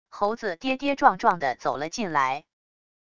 猴子跌跌撞撞地走了进来wav音频生成系统WAV Audio Player